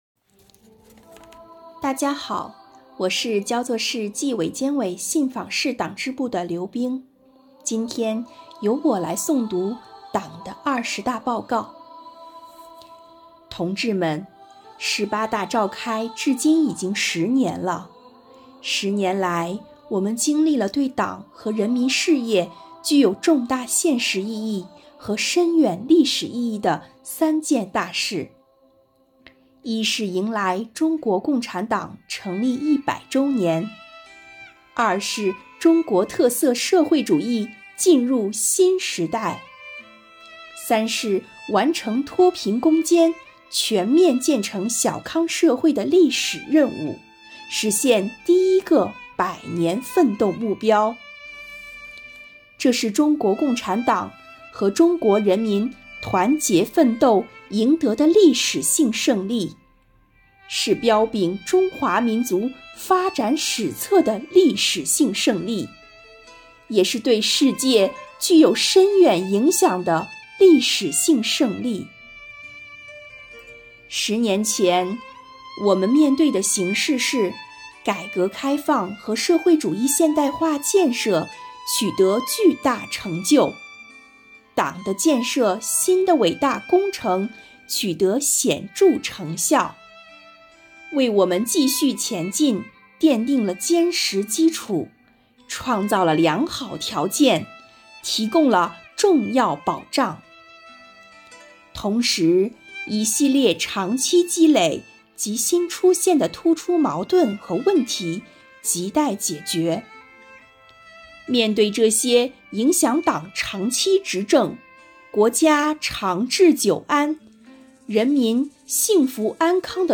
诵读内容